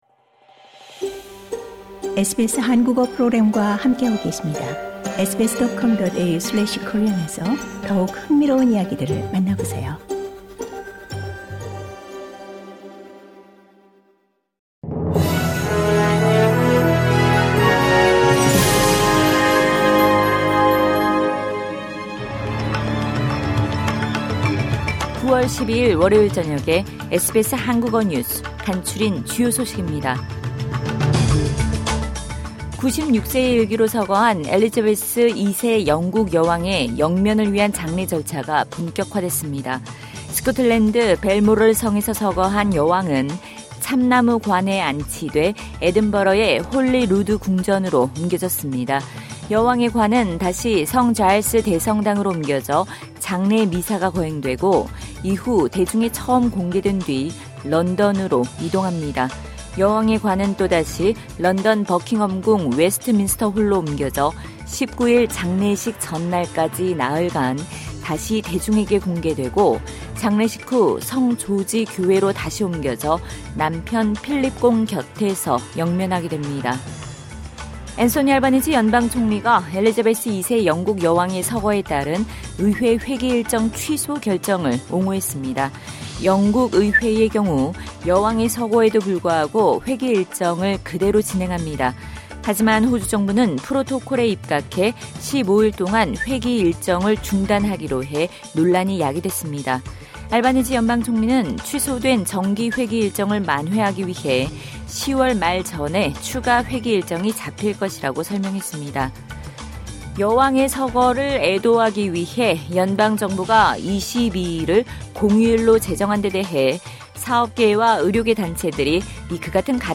SBS 한국어 저녁 뉴스: 2022년 9월 12일 월요일